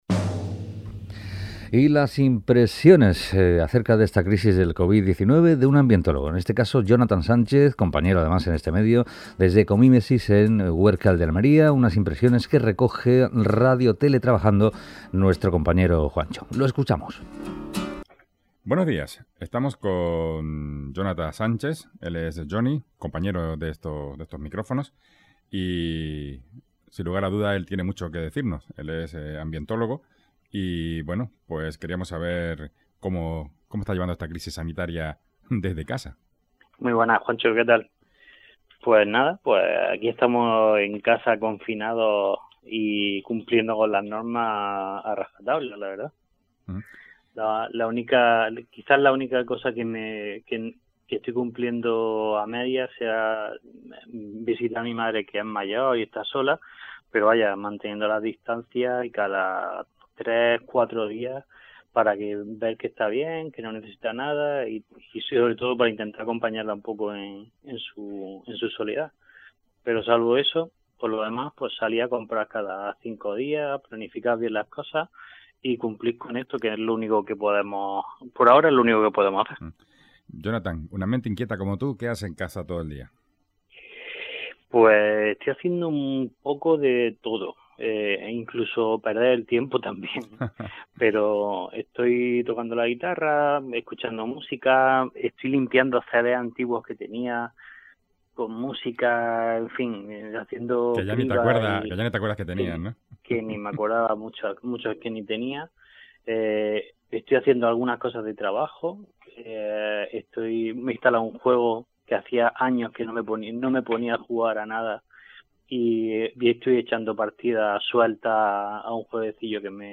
dialoga con nuestro compañero